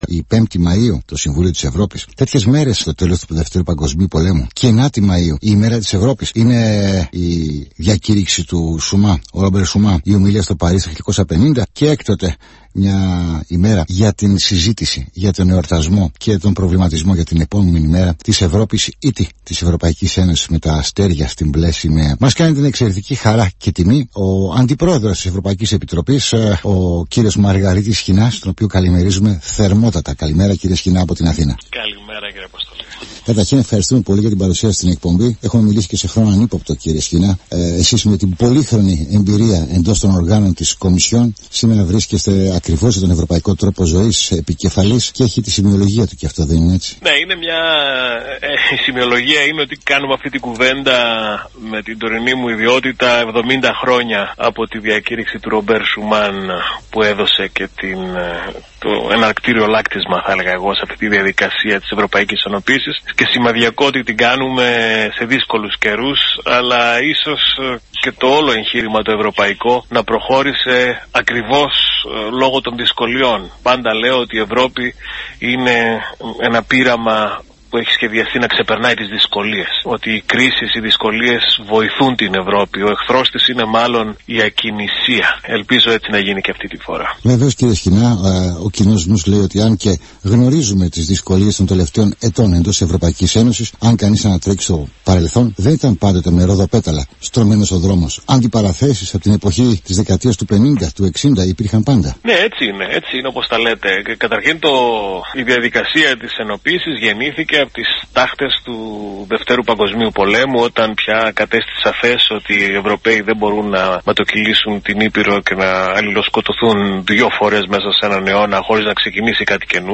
Τη σημασία της αλληλεγγύης και συνεργασίας μεταξύ των Ευρωπαίων, υπογράμμισε μιλώντας στον Αθήνα 9.84 ο αντιπρόεδρος της Ευρωπαϊκής Επιτροπής Μαργαρίτης Σχοινάς.